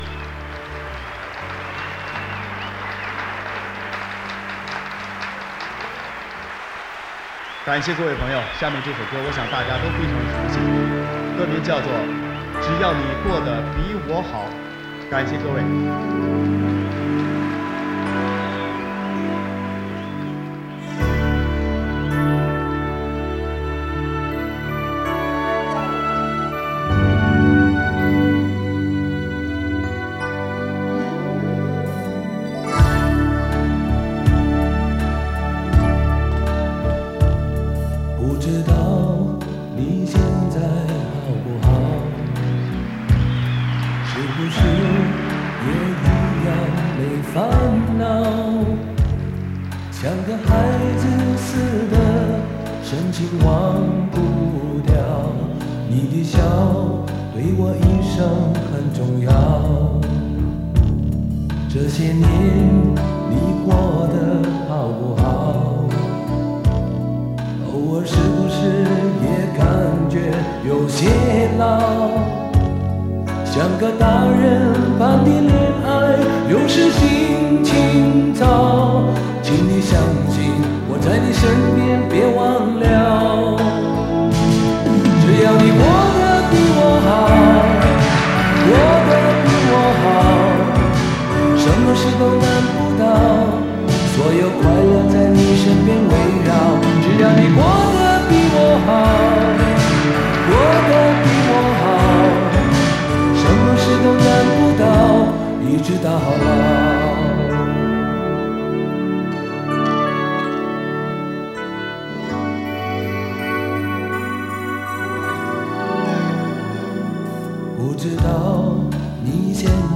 磁带数字化：2022-10-15